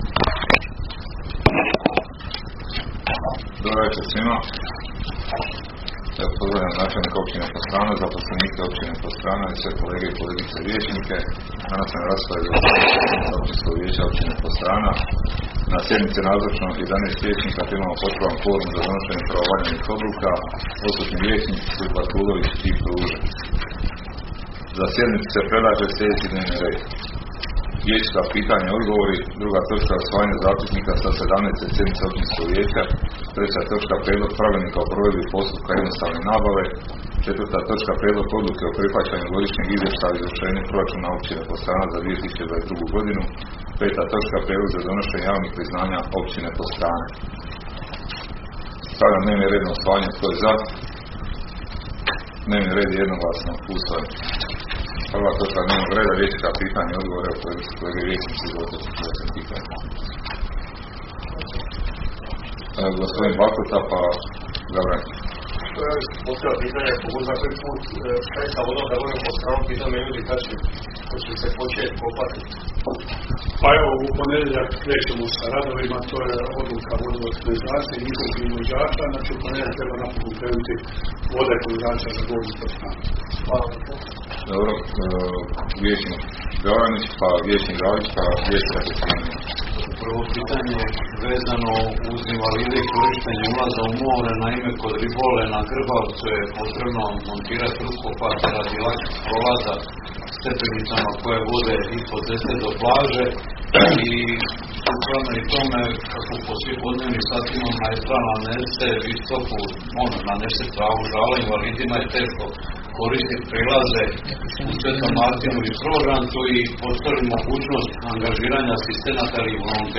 Sjednica će se održati dana 31. svibnja (srijeda) 2023. godine u 19,00 sati u vijećnici Općine Podstrana.